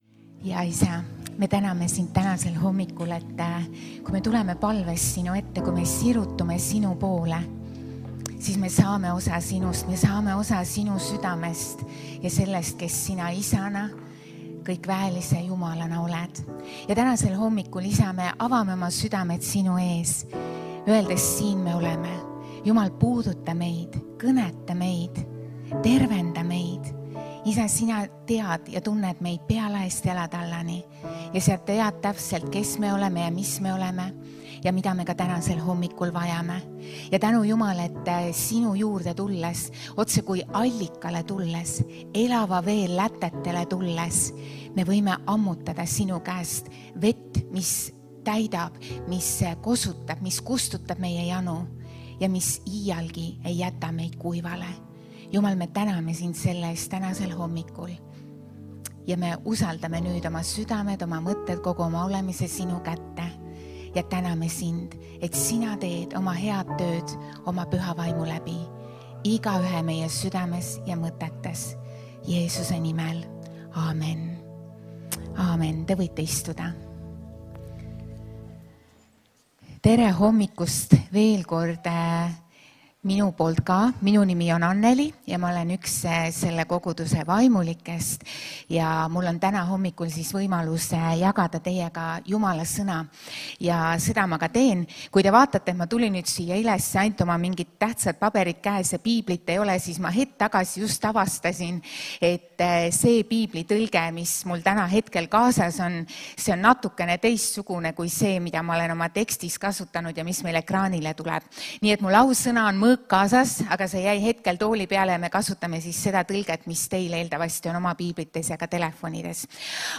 Jutlused - EKNK Toompea kogudus